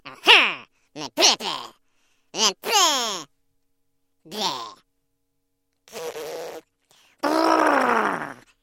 Смешной голосок червячка